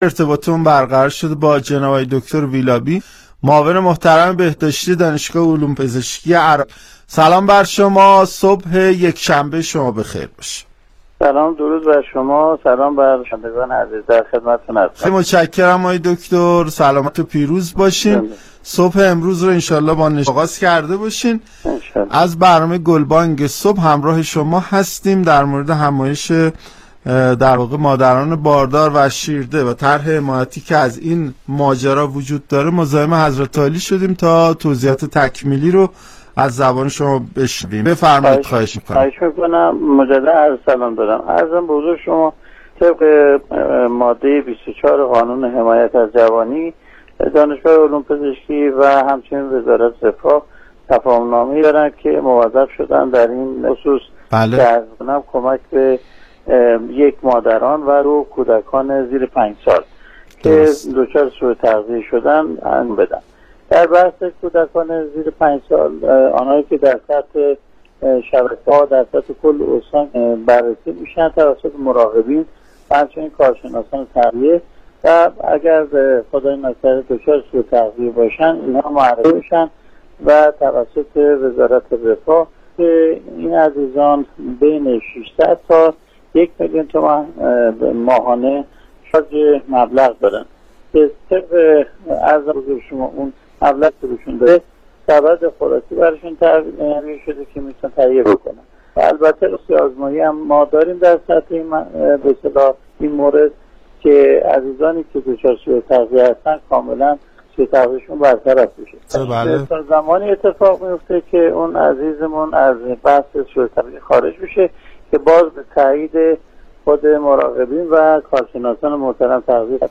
برنامه رادیویی گلبانگ صبح
دکتر اردلان زیلابی معاون بهداشتی و رئیس مرکز بهداشت استان مرکزی